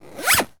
foley_zip_zipper_short_05.wav